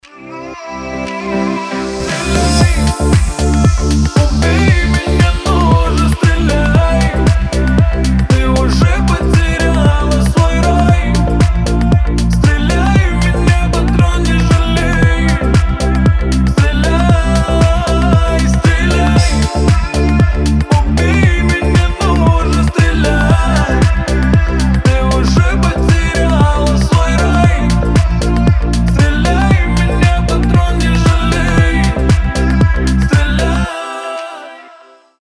• Качество: 320, Stereo
мужской голос
deep house
грустные